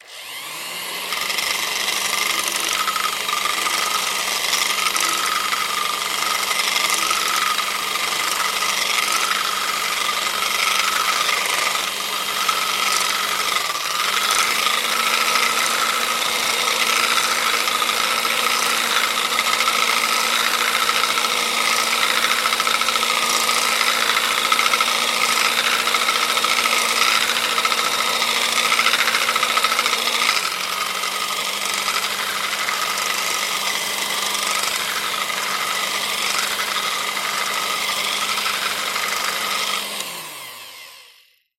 Звуки взбитого омлета в металлической миске миксером